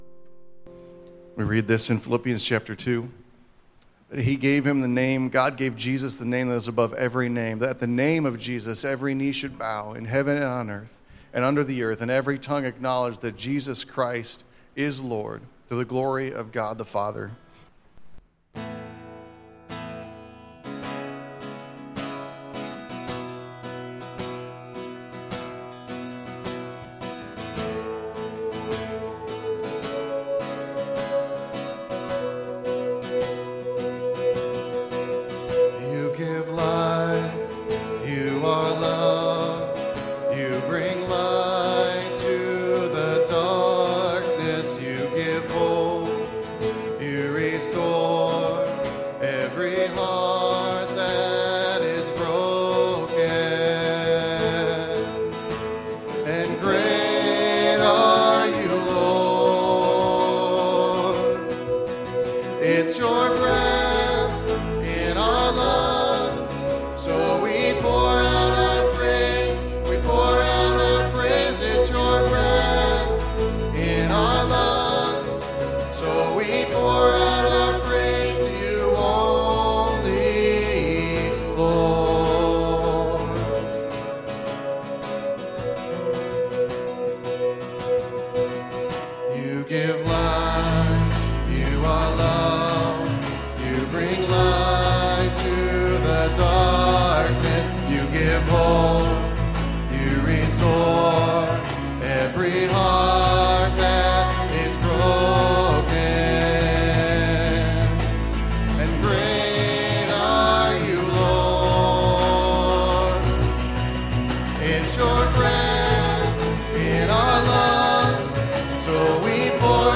John 1:35-46 Service Type: Sunday AM Bible Text